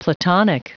Prononciation du mot platonic en anglais (fichier audio)
Prononciation du mot : platonic